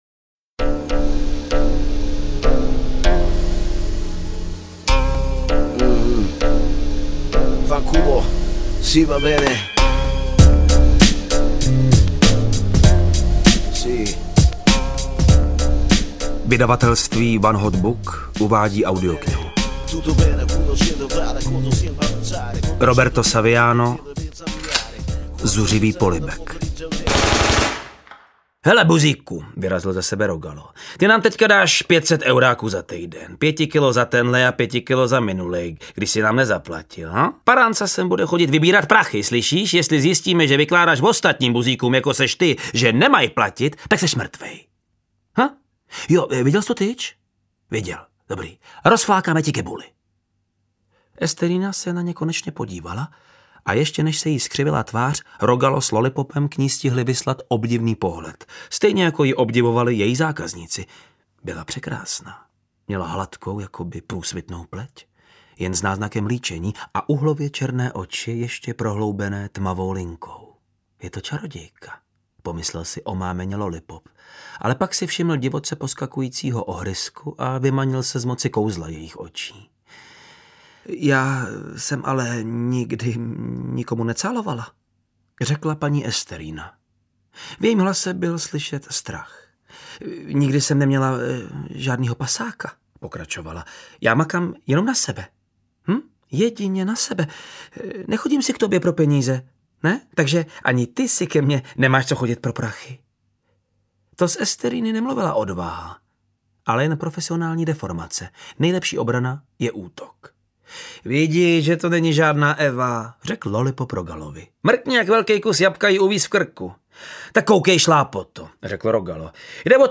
Zuřivý polibek audiokniha
Ukázka z knihy
• InterpretVáclav Neužil